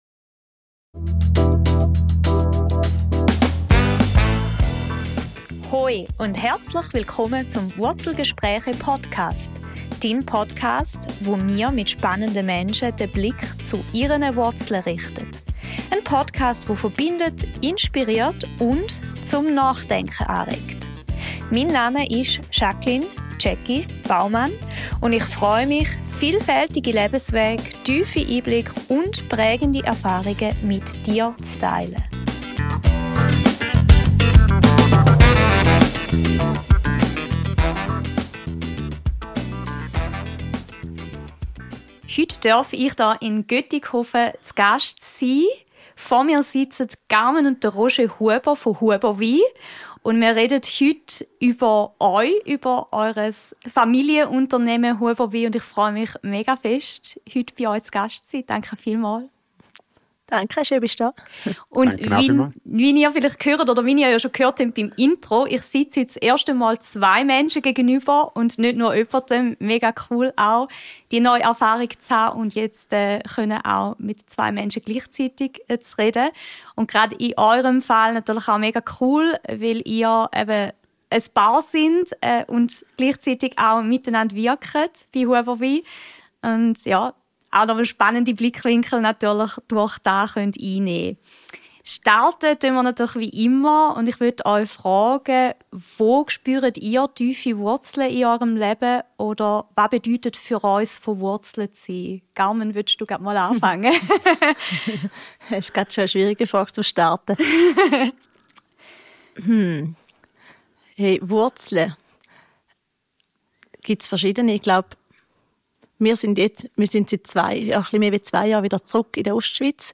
In dieser Folge der Wurzelgespräche habe ich zum ersten Mal 2 Gesprächspartner vor den Mikrofonen.